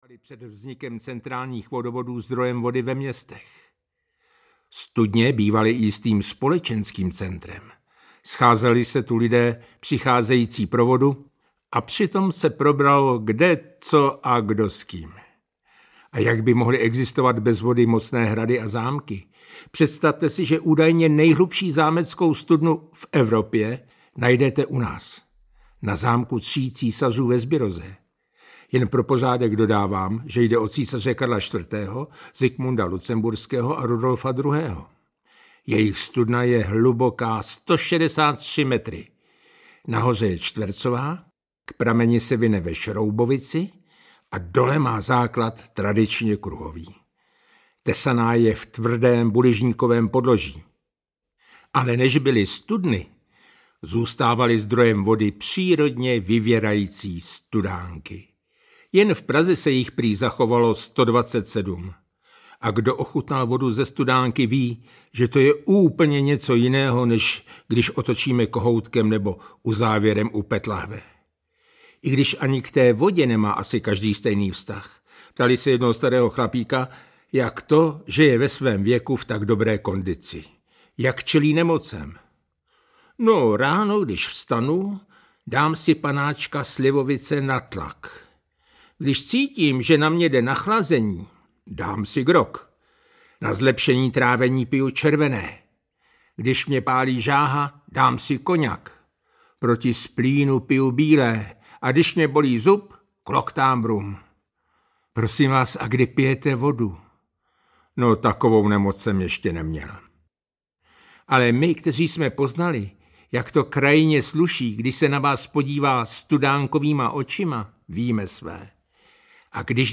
Autorská četba z knihy Jak se hladí duše (2020), souborů drobných textů a básní Studánky v srdci, Chvála otázek, Jak číst myšlenky, O hradech z písku, Jak jsem ani tentokrát neporazil Františka Nepila, O tatínkovi, Proč pastýři nezbloudí, Neboj ty…
Ukázka z knihy
jak-se-hladi-duse-audiokniha